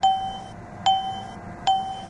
描述：这是美国银行ATM机的录音。 这是当它试图提醒用户将他或她的ATM卡从机器中取出时发出的声音。 背景的嗡嗡声是ATM机和外界的噪音。